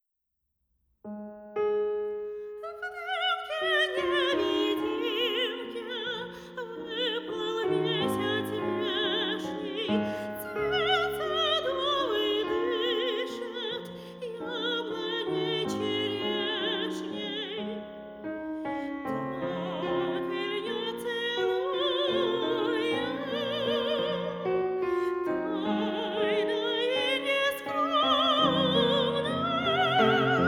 aufgenommen Herbst 2021 im Tonstudio